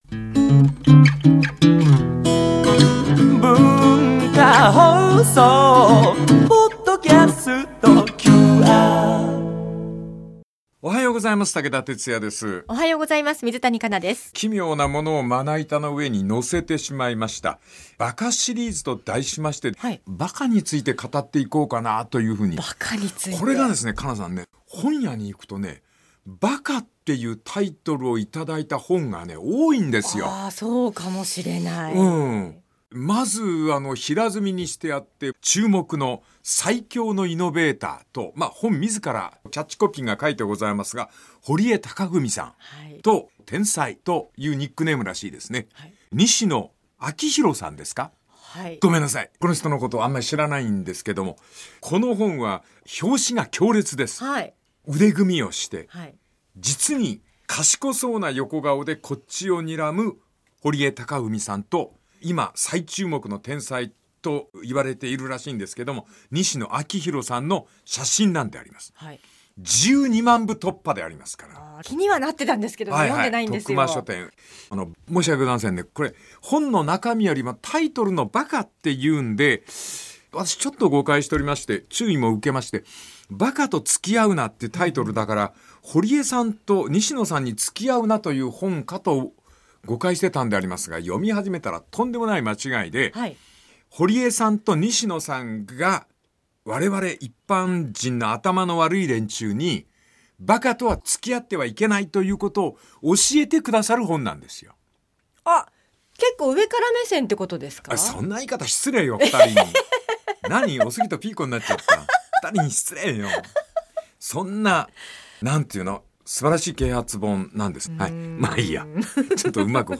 温かさと厳しさを併せ持つ武田鉄矢が毎週テーマに添ってさまざまな語りを展開。